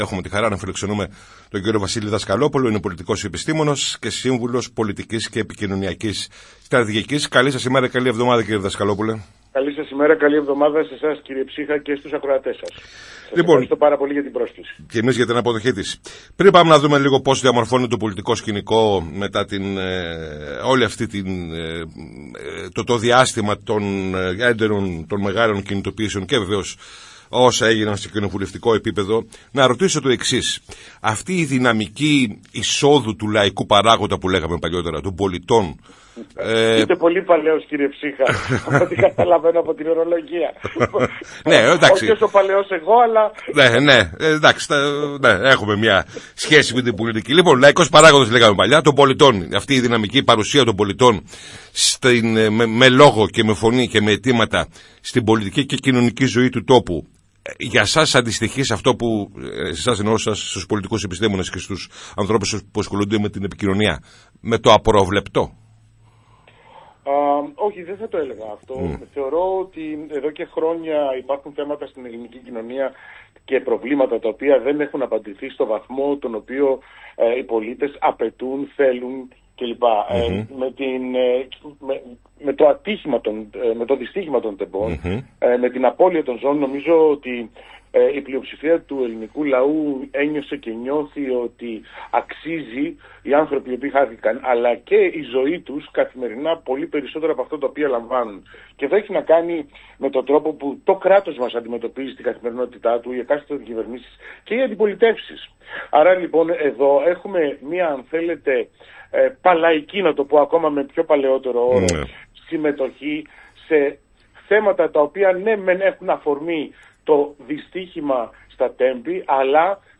Για τις πολιτικές εξελίξεις και τις στρατηγικές της κυβέρνησης και των κομμάτων της αντιπολίτευσης, μετά τις μεγαλειώδεις συγκεντρώσεις και τις αντιπαραθέσεις εντός Βουλής, για την τραγωδία των Τεμπών, μίλησε στη ραδιοφωνική εκπομπή της ΕΡΤ Λάρισας «Πολιτικό Ημερολόγιο»